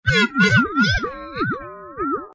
robot_talking.ogg